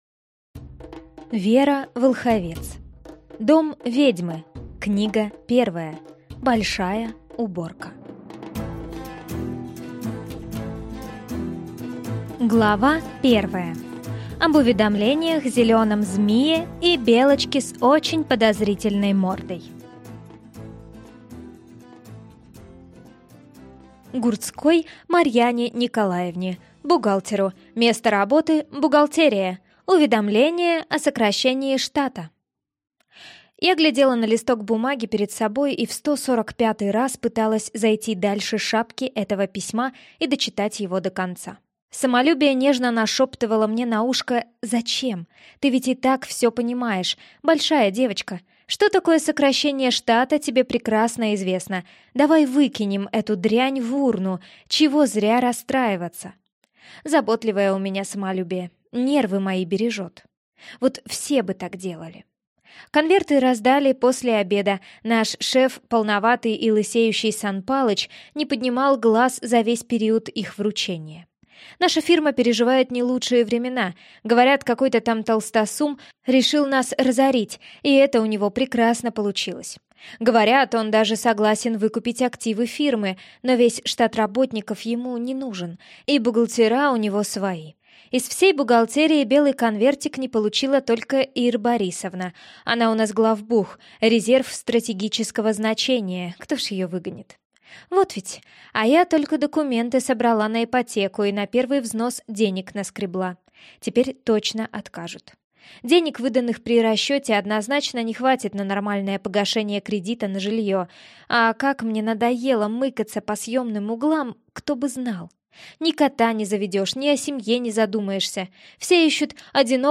Аудиокнига Дом ведьмы. Книга 1. Большая уборка | Библиотека аудиокниг